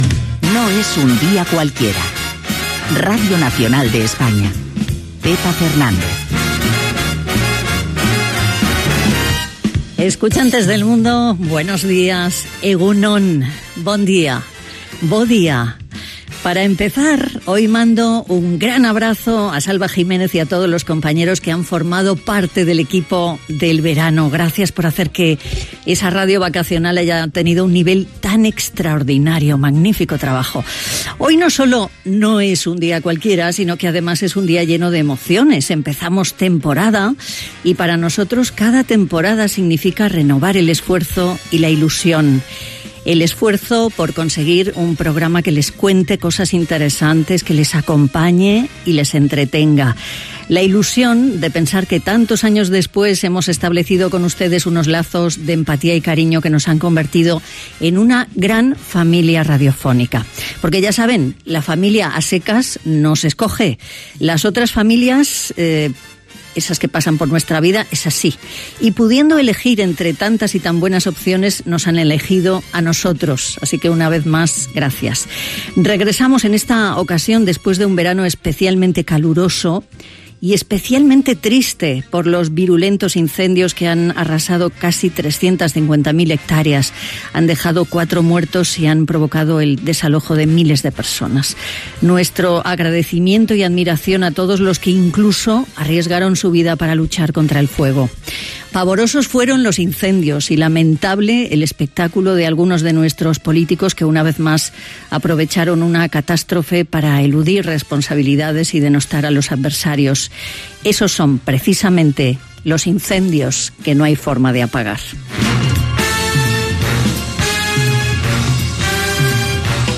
Careta del programa.
Entreteniment